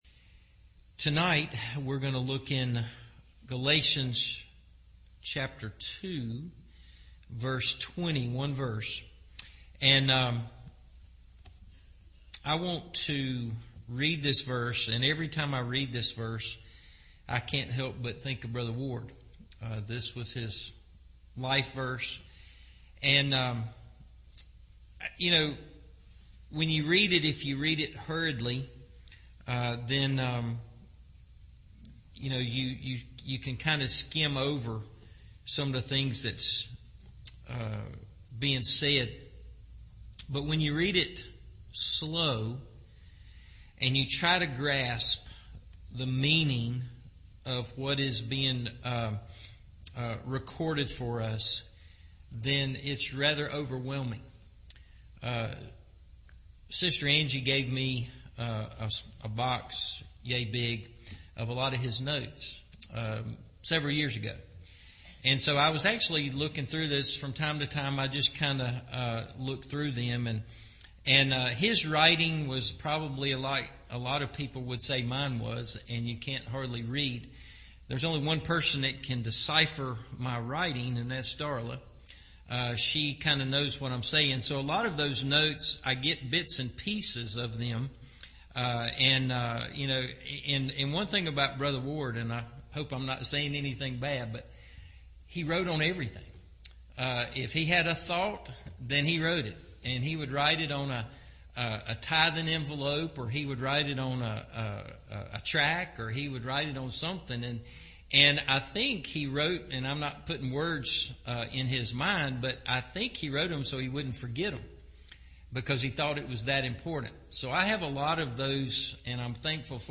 Jesus Loved Me And Gave Himself For Me – Evening Service